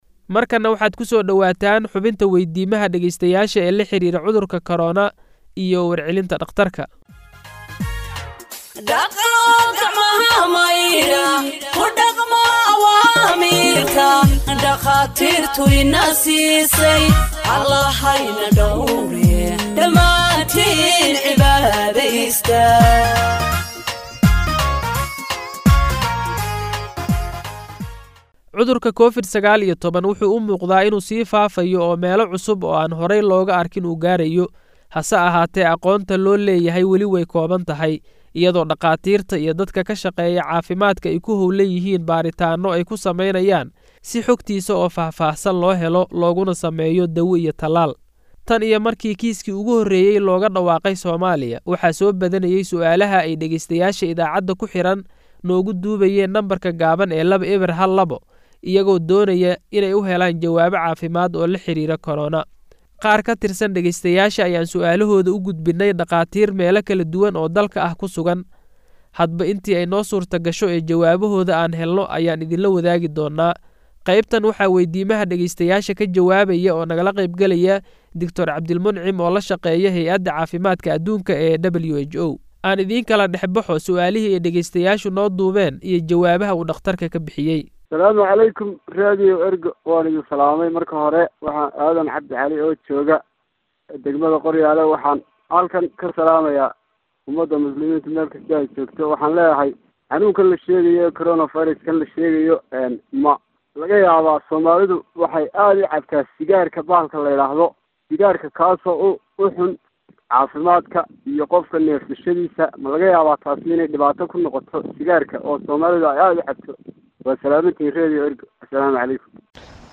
Health expert answers listeners’ questions on COVID 19 (4)